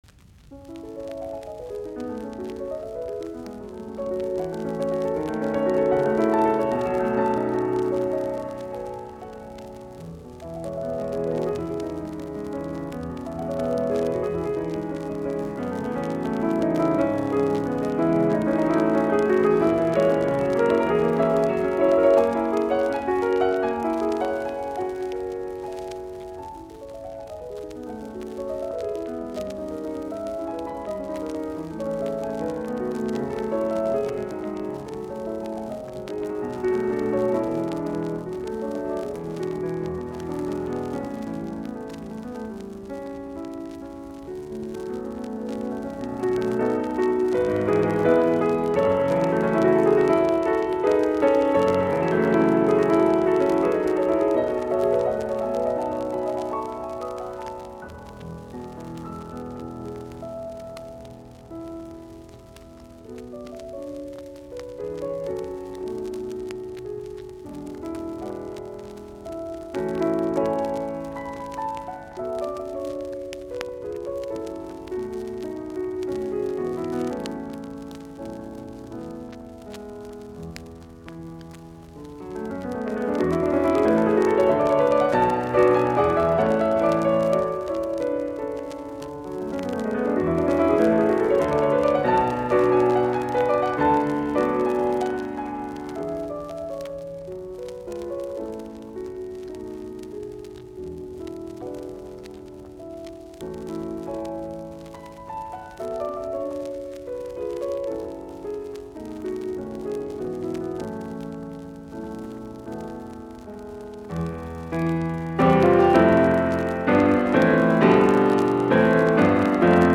piano
Soitinnus : Piano